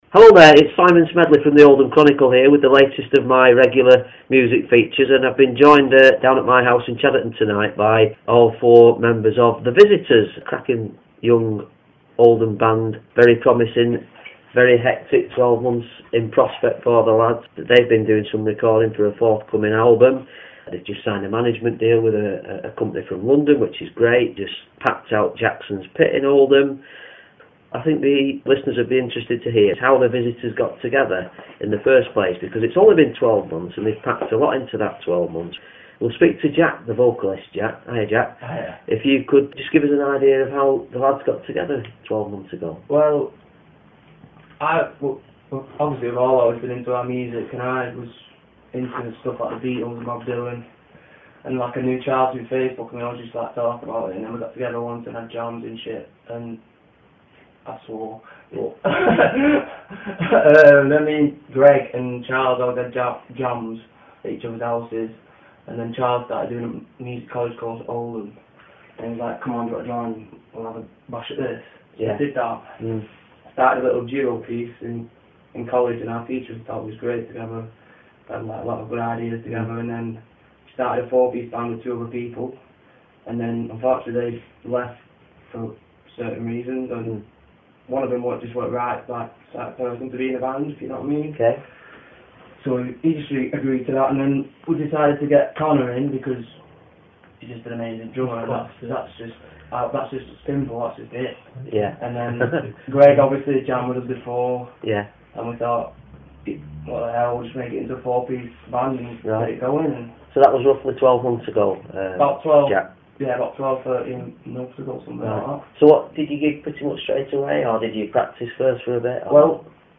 chatting to the widely-influenced band